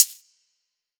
ClosedHH MadFlavor 4.wav